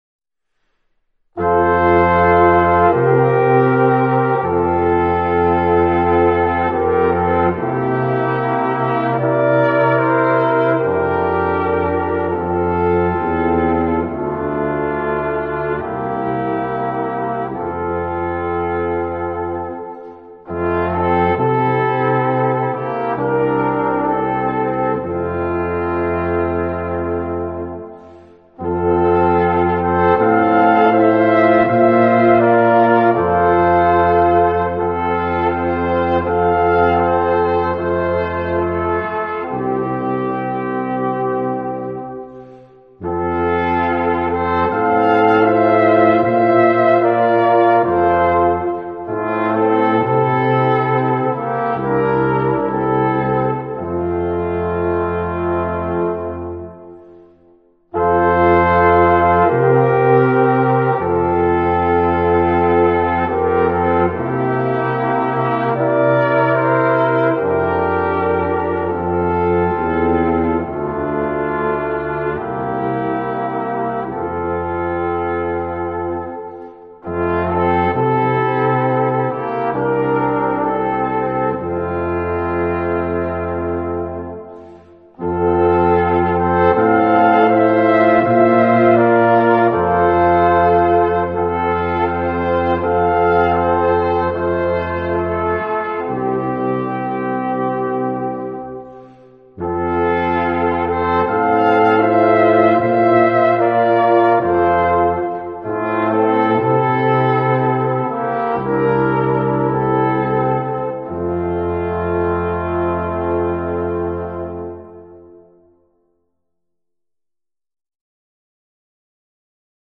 Gattung: 4 Blechbläser
Besetzung: Ensemblemusik für 4 Blechbläser
1./2. Flügelhorn - Posaune - Tuba.